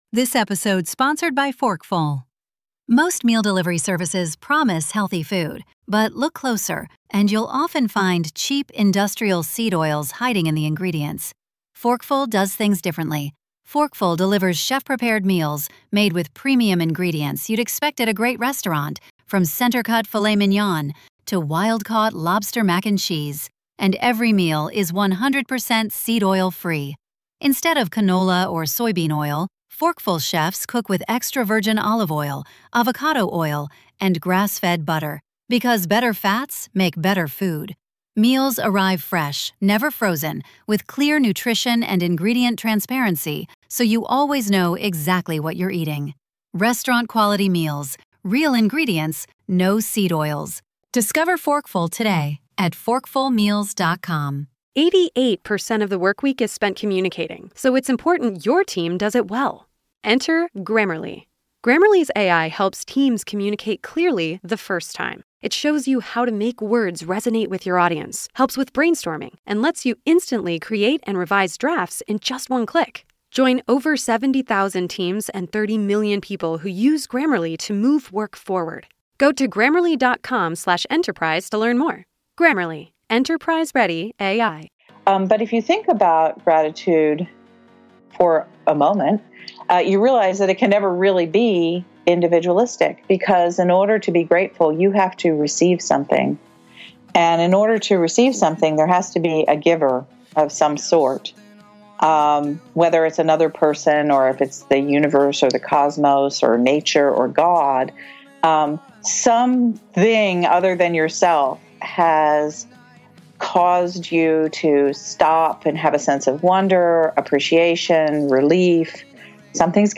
Guest Bio: This week we welcome back one of our favorite guests Dr. Diana Butler Bass!